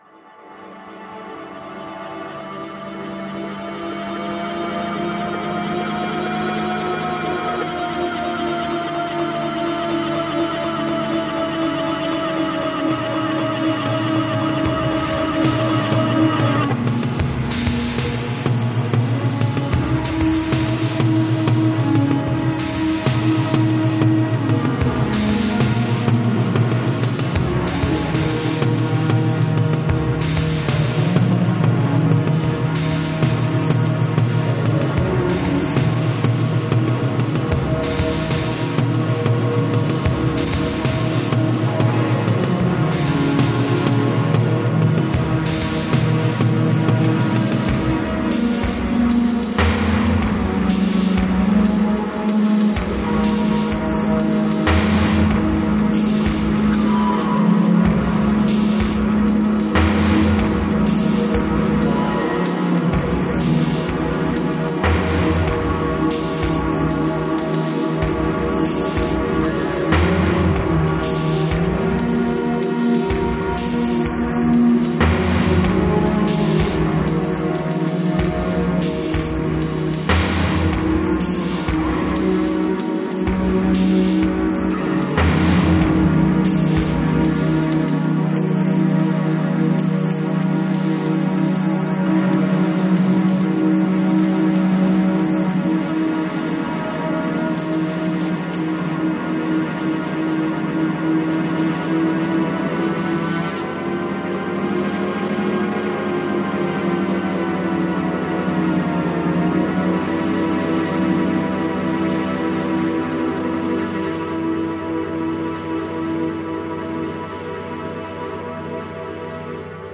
Another piece from old tape: